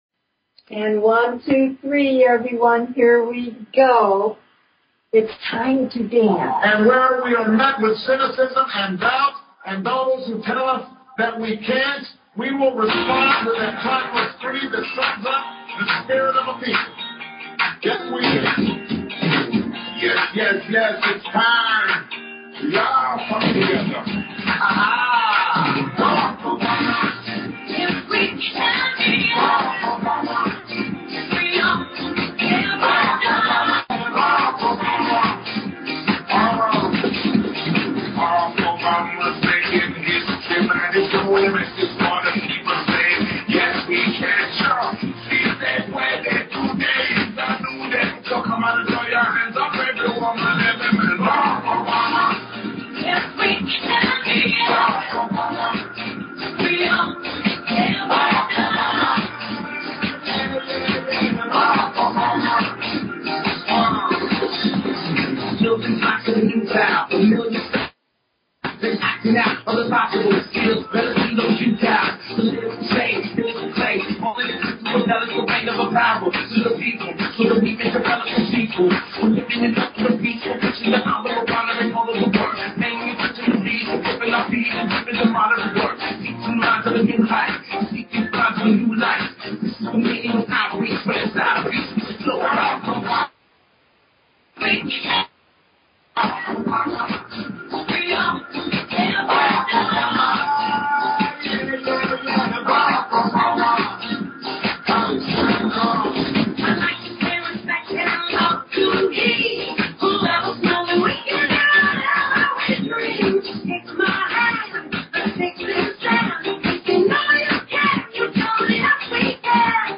Talk Show Episode, Audio Podcast, Galactic_Healing and Courtesy of BBS Radio on , show guests , about , categorized as